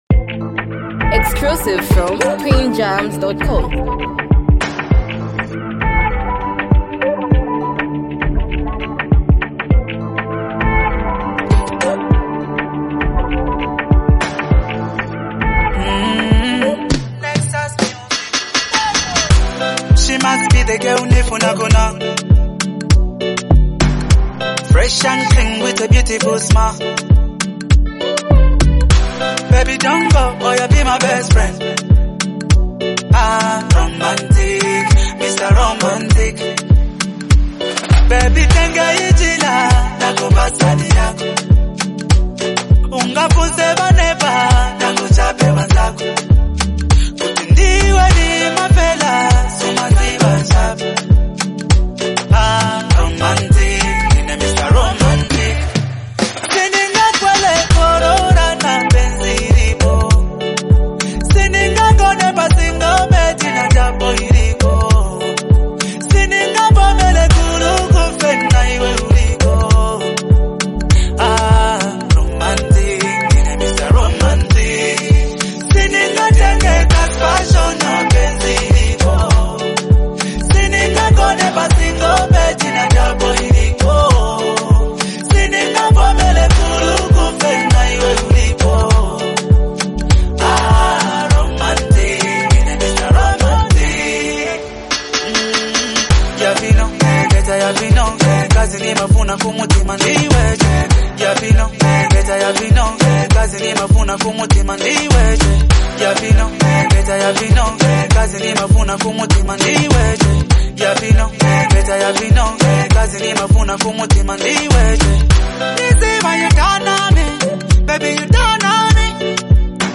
hype, love vibes, and a powerful hook
unique singing flow